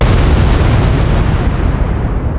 krachts.wav